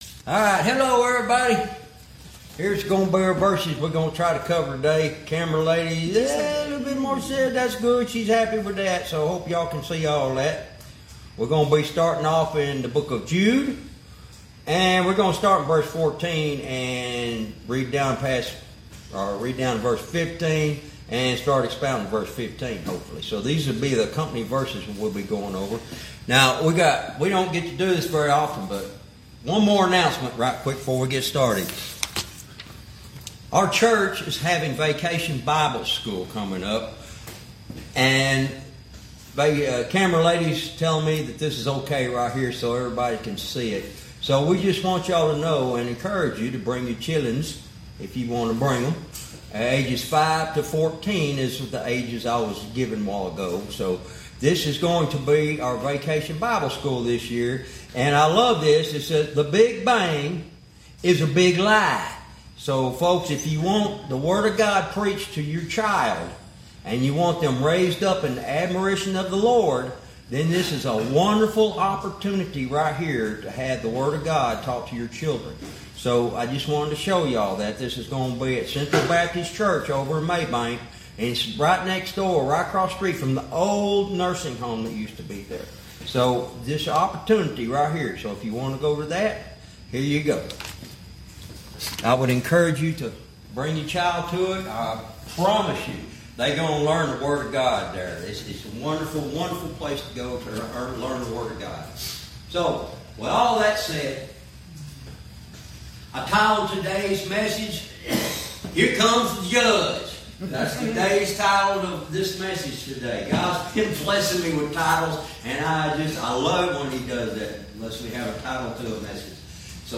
Verse by verse teaching - Jude lesson 64 verse 15 " Here Comes the Judge"